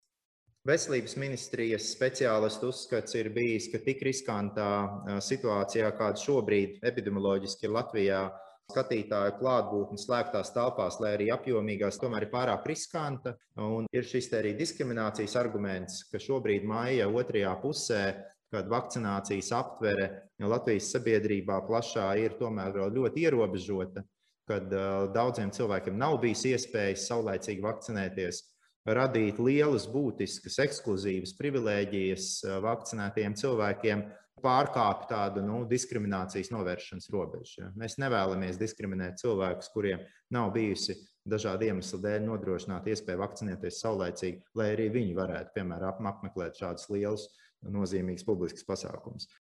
Turpina veselības ministrs Daniels Pavļuts: